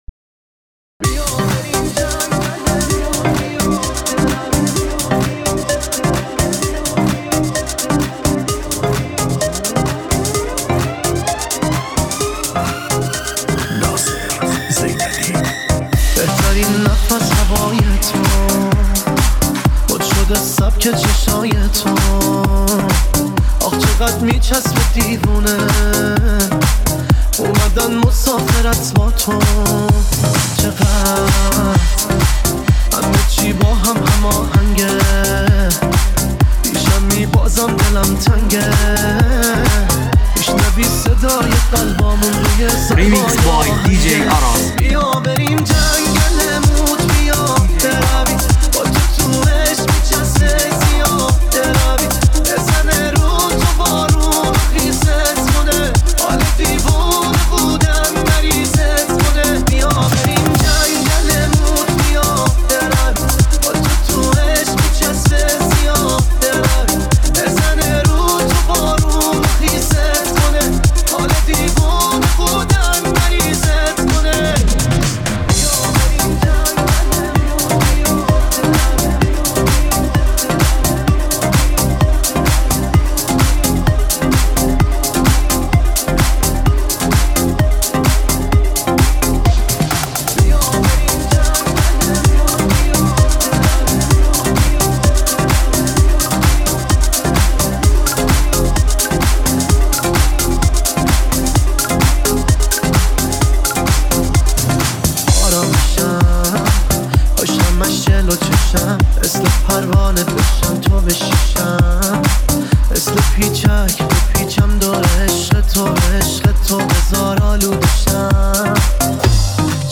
ریمیکس